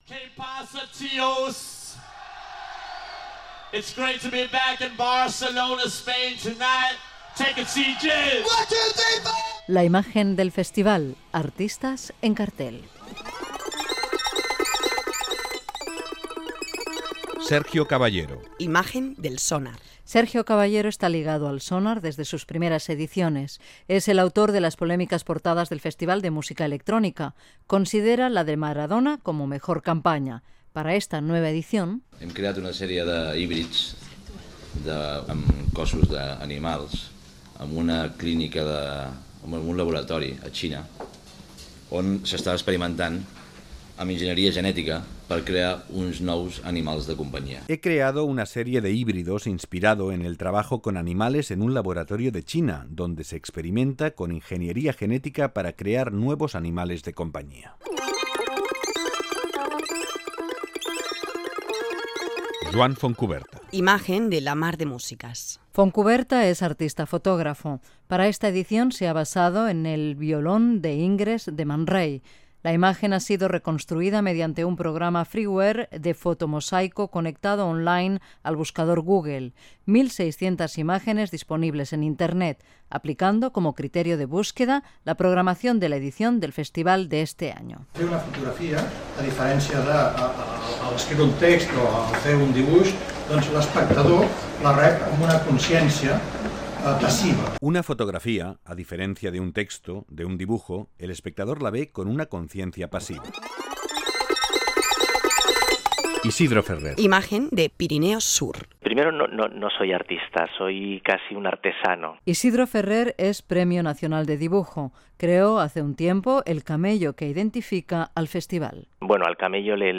Espai dedicat a les imatges dels cartells del Festival Sónar, La Mar de Músicas, Pirineos Sur, Jazz al Día, amb declaracions dels seus creadors